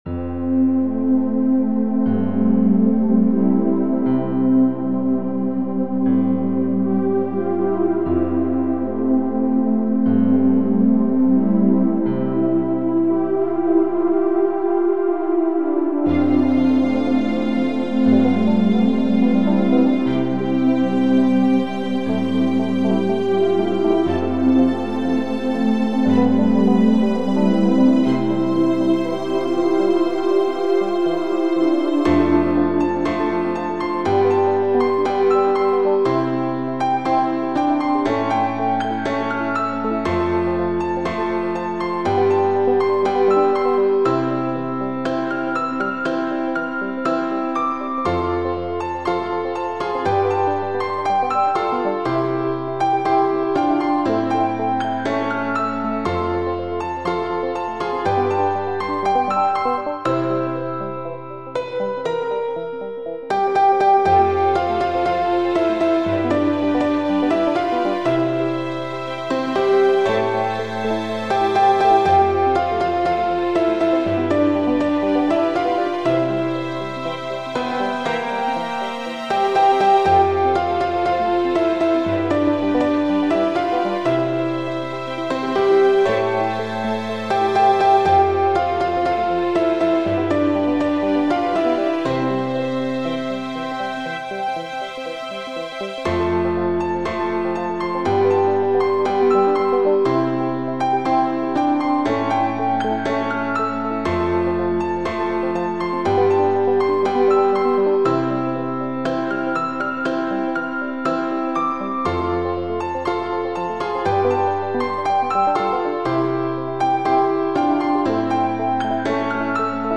/ Classical / Orchestral